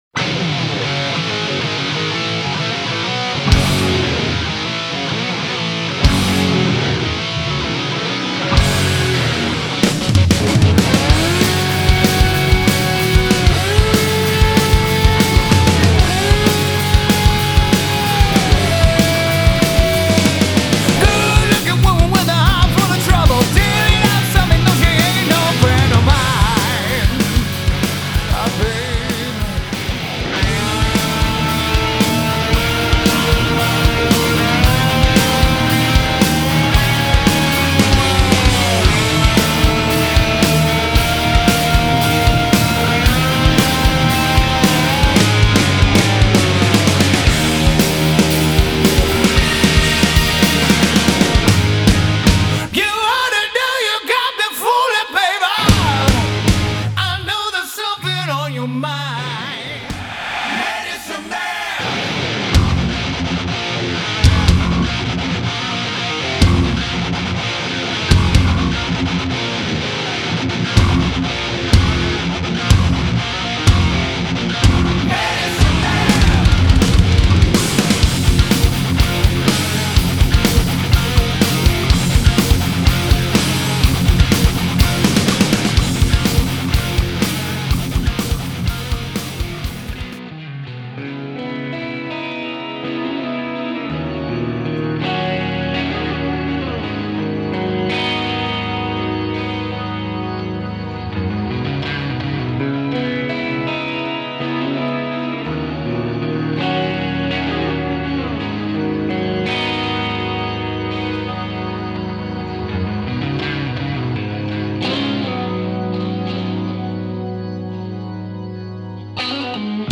This is old school Head Banging Rock n Roll at its best.